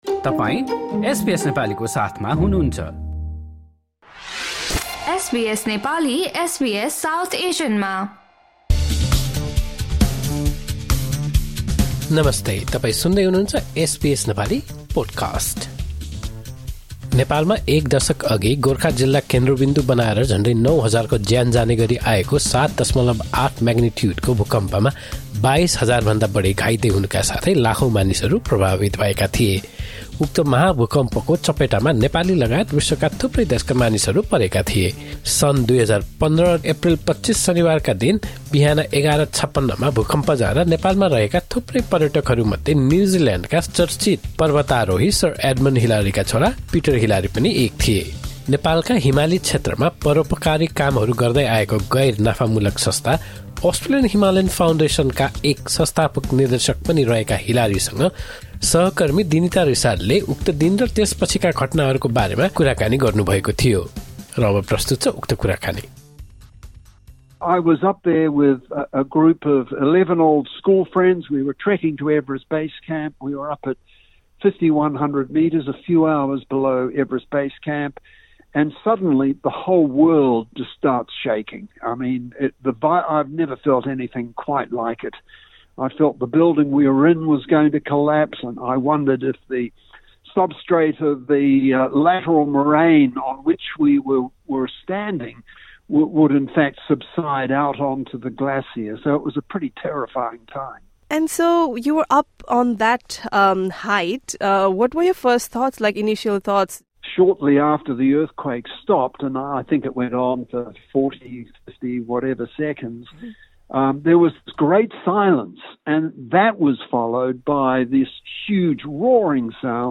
नेपालका हिमाली क्षेत्रमा परोपकारी कामहरू गर्दै आएको गैर नाफामूलक संस्था अस्ट्रेलियन हिमालयन फाउन्डेसनका एक संस्थापक निर्देशक पनि रहेका पिटर हिलारीसँग उक्त दिन र त्यसपछिका घटनाहरूका बारेमा एसबीएस नेपालीले गरेको कुराकानी सुन्नुहोस्।